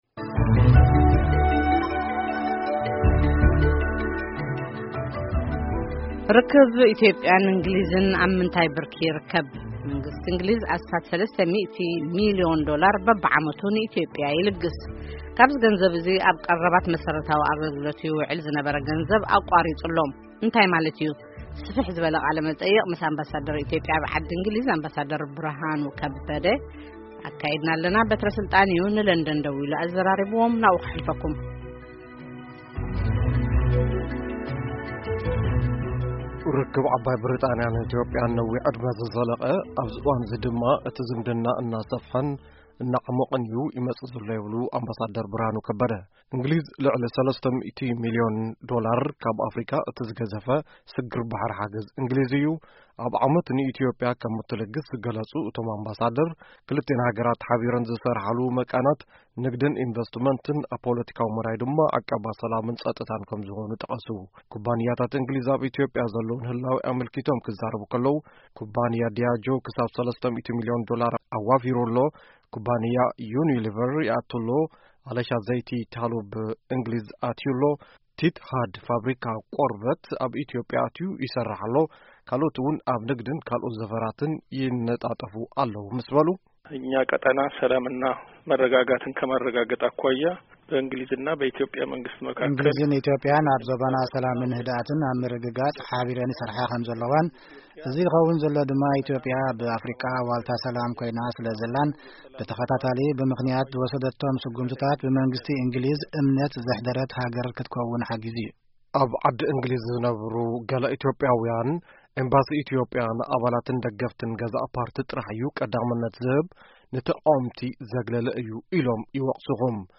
ቃለ መጠይቅ ምስ ኣምባ. ብርሃኑ ከበደ